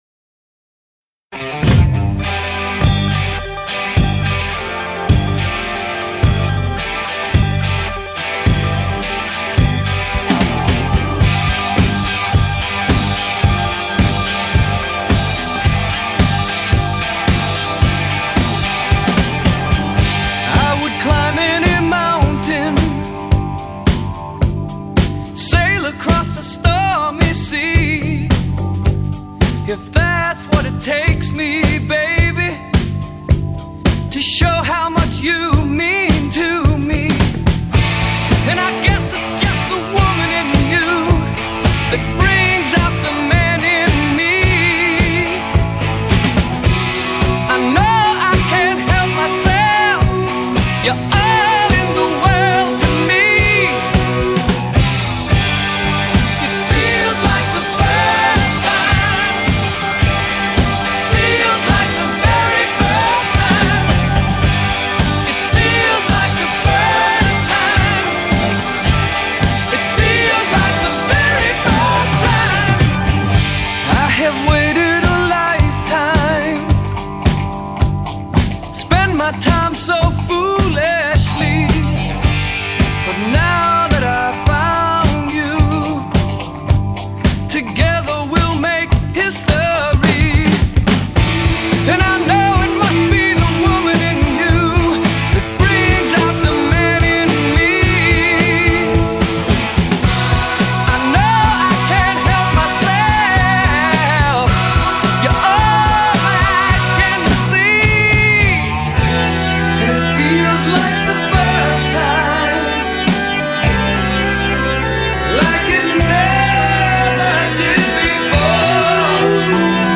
Rock, Hard Rock